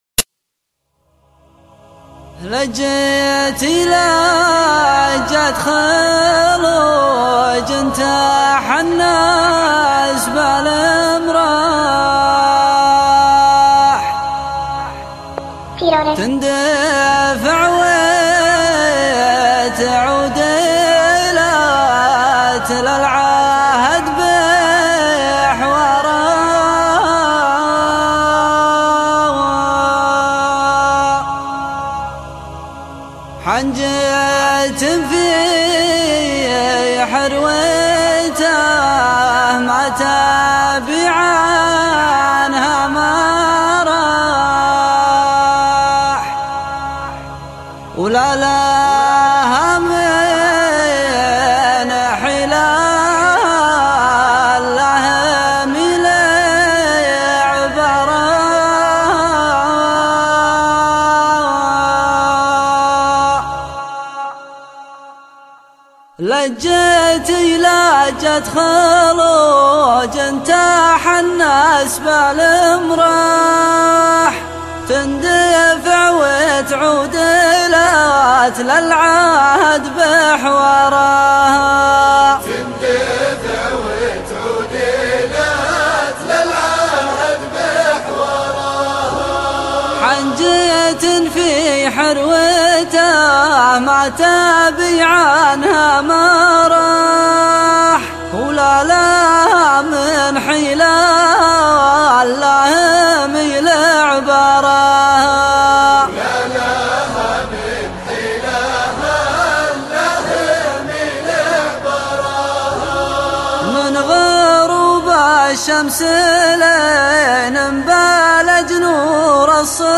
موال+شيلة